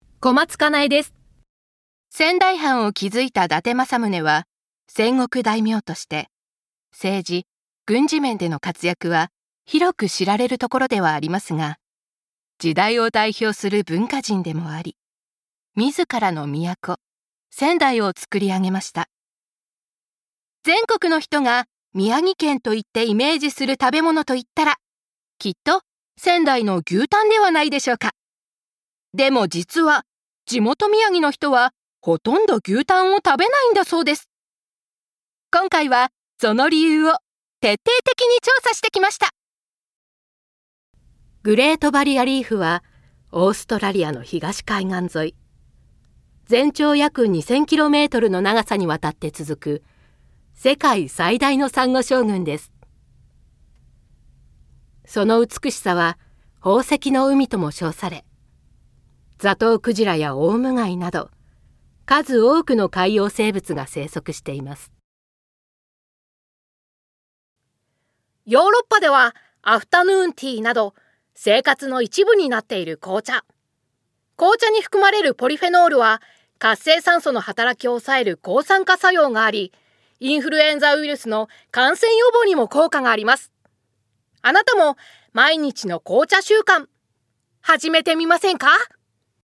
方言　 ： 東北弁
◆ナレーション